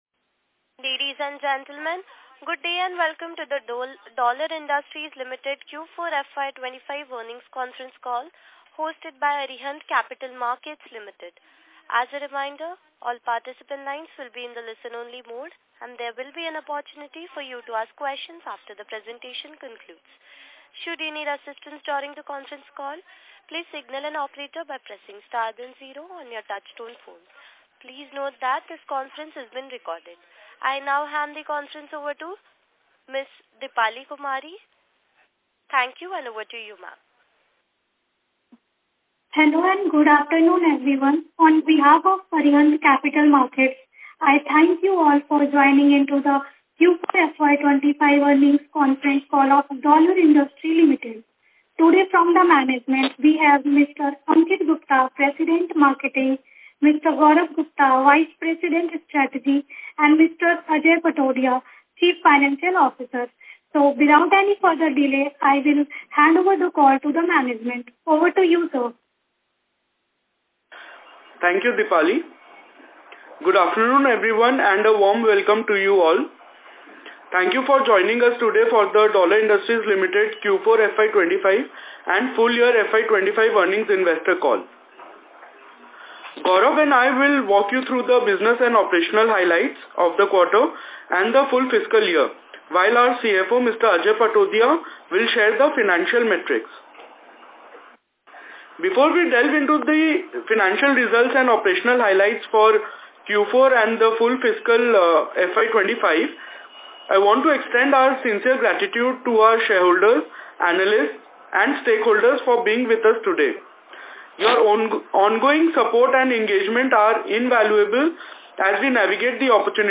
Concall Recording – Dollarglobal
Dollar Ind – Q1FY26 Conference Call